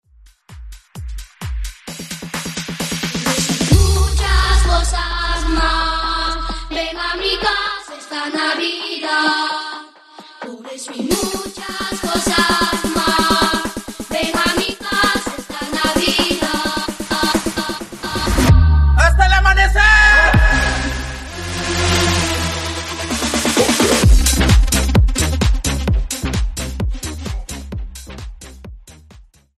Clean $ 3.00 130